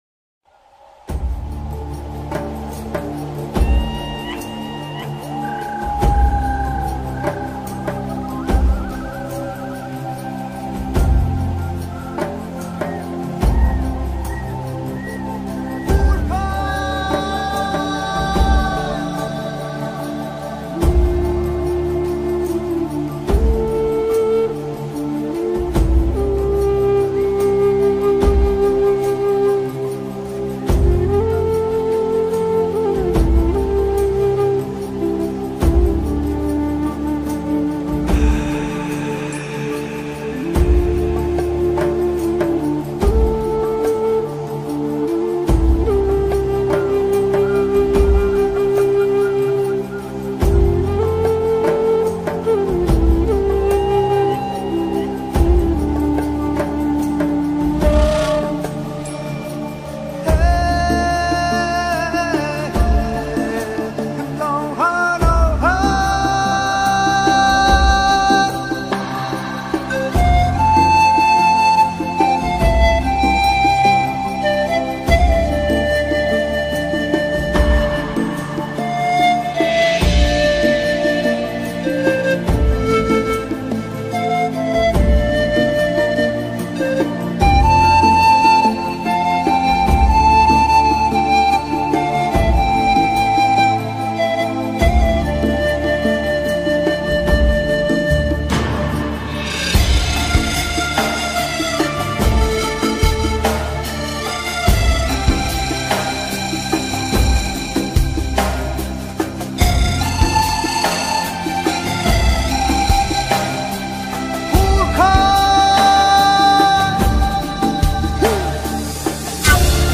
آهنگ بی کلام و ارامش بخش
دانلود آهنگ بیکلام فوق العاده آرامبخش